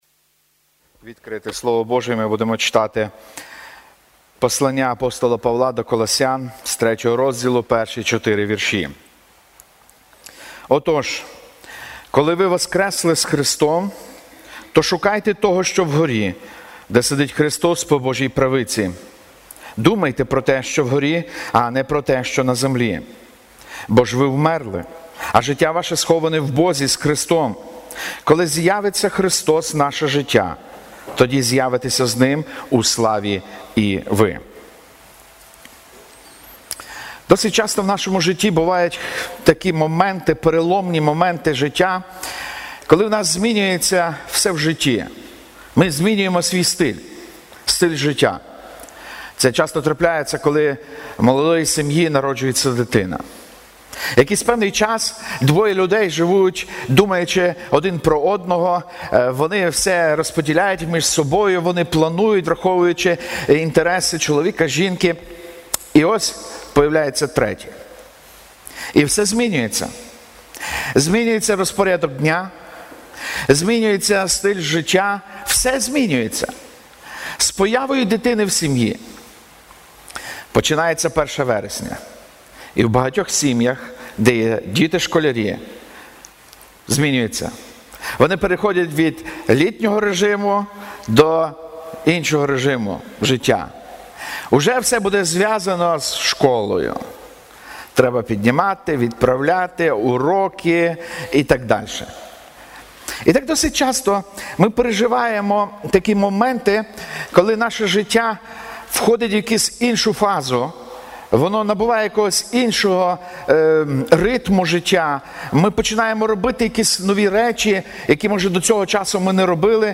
Трускавець Церква Преображення
проповіді